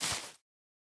Minecraft Version Minecraft Version 1.21.5 Latest Release | Latest Snapshot 1.21.5 / assets / minecraft / sounds / block / spore_blossom / step6.ogg Compare With Compare With Latest Release | Latest Snapshot